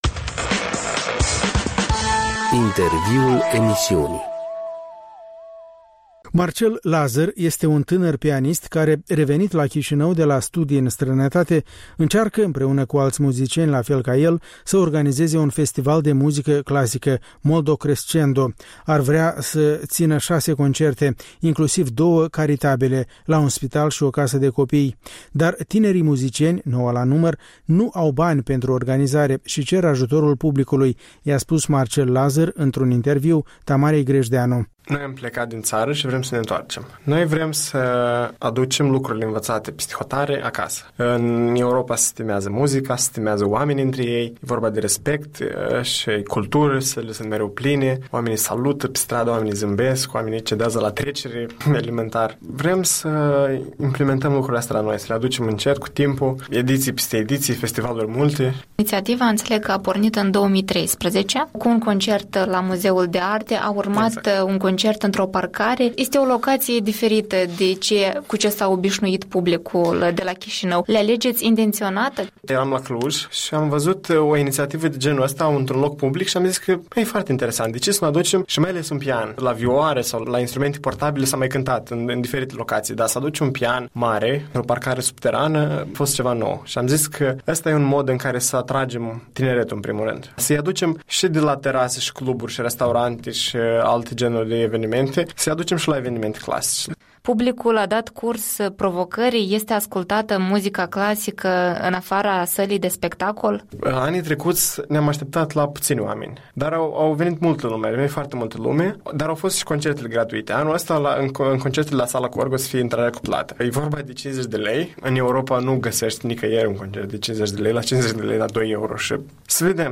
Interviuri la REL